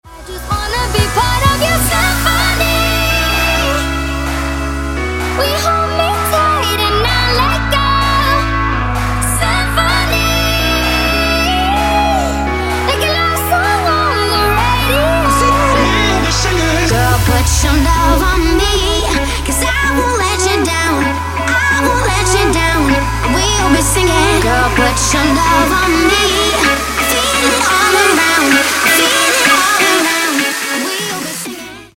• Качество: 256, Stereo
поп
женский вокал
dance
club
mash up